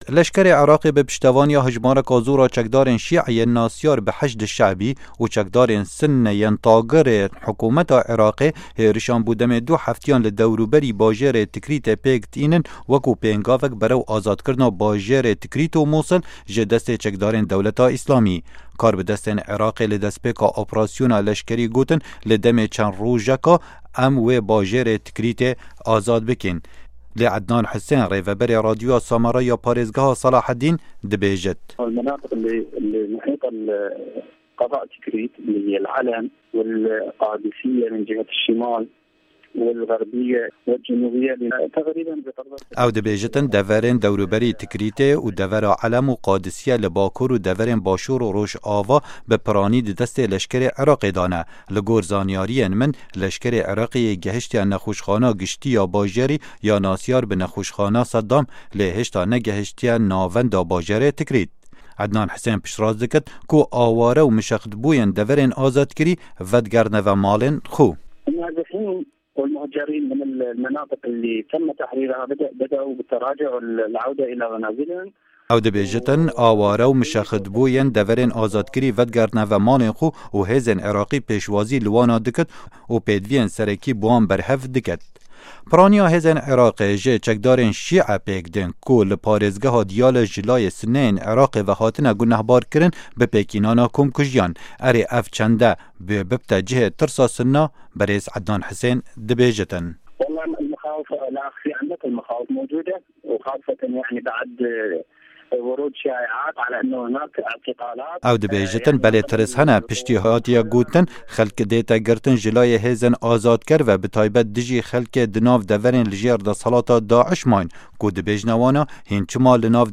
hevpeyvin digel